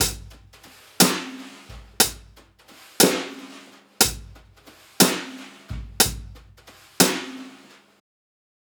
Unison Jazz - 5 - 120bpm - Tops.wav